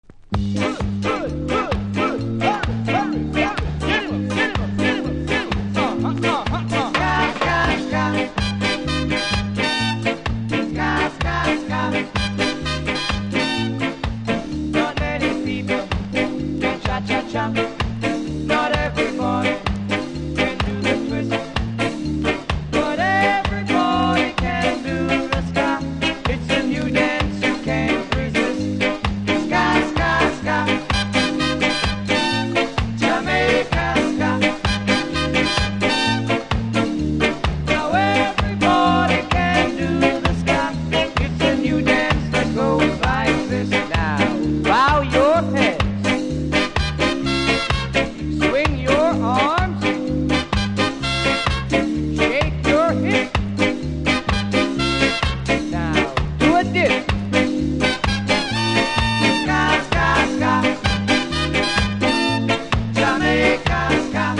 キズそこそこありますがノイズは少なく気にならない程度。